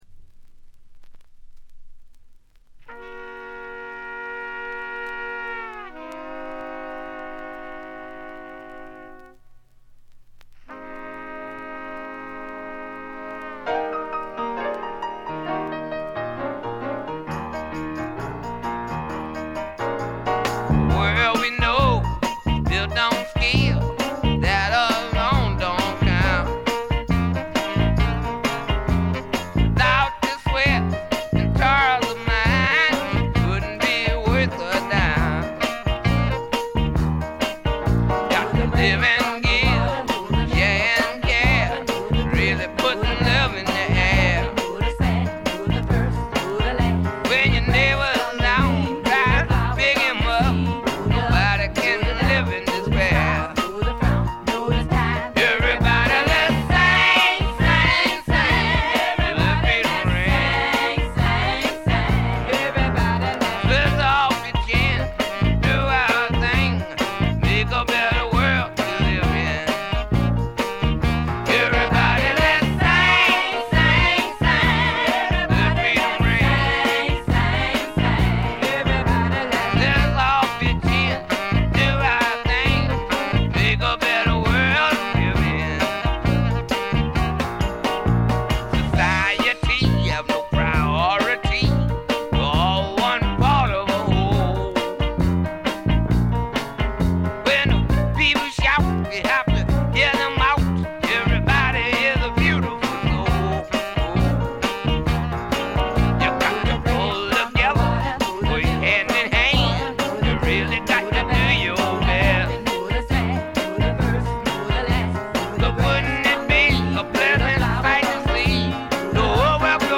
部分試聴ですが、静音部での軽微なチリプチ程度。
試聴曲は現品からの取り込み音源です。